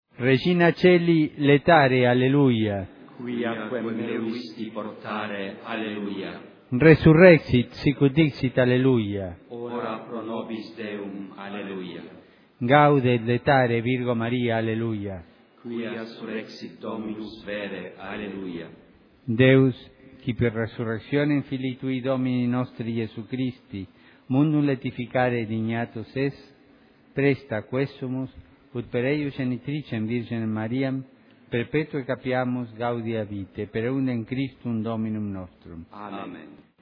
Audio do Regina Caeli em Latim, pelo Papa Francisco